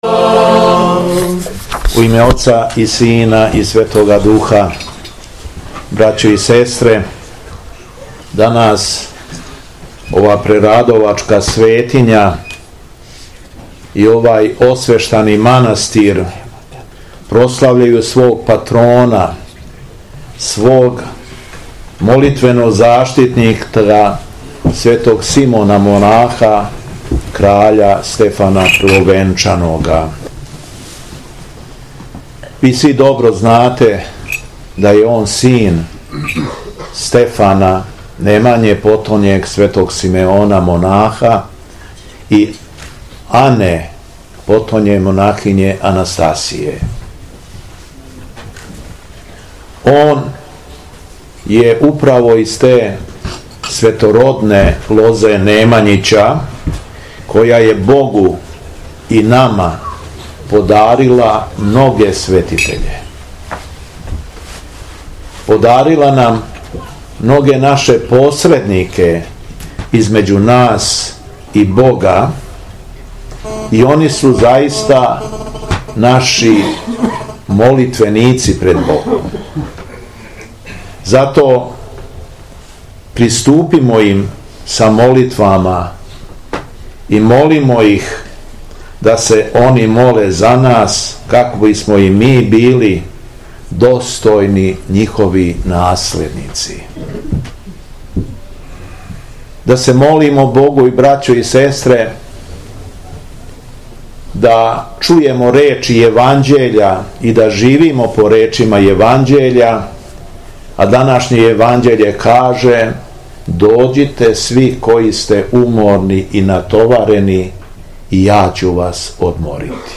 Беседа Његовог Преосвештенства Епископа шумадијског г. Јована
Након торженственог бденија уследила је трпеза љубави у манастирском конаку.